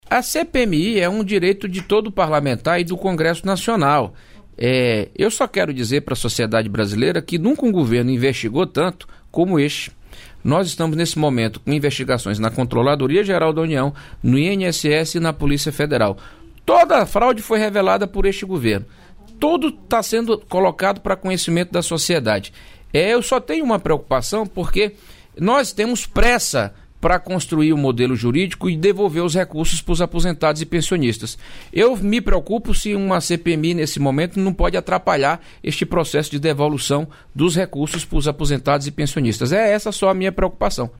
Trecho da participação do ministro da Advocacia-Geral da União, Jorge Messias, no programa "Bom Dia, Ministro" desta terça-feira (20), nos estúdios da EBC em Brasília (DF).